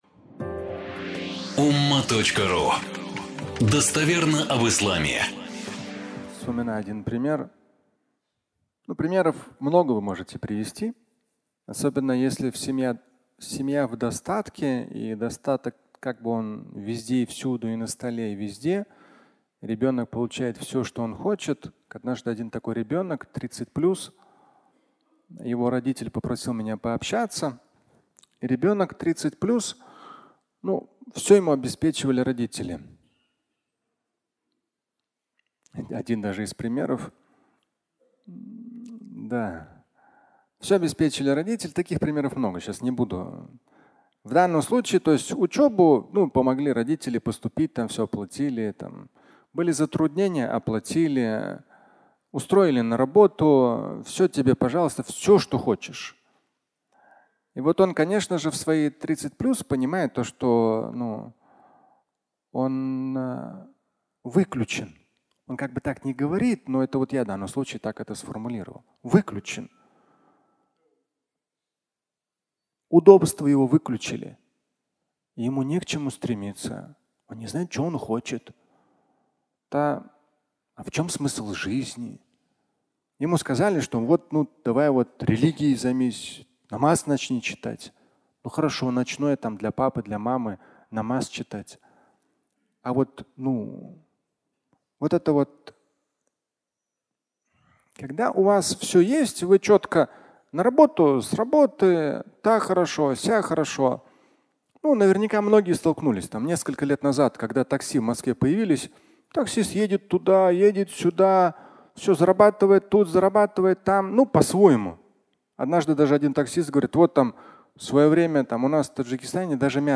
Фрагмент пятничной лекции, в котором Шамиль Аляутдинов говорит о необходимости выходить из зоны комфорта.